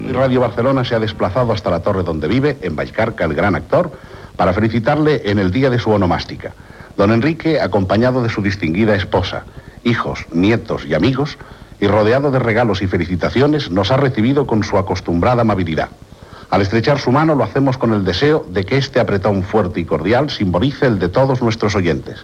Extret de Crònica Sentimental de Ràdio Barcelona emesa el dia 8 d'octubre de 1994.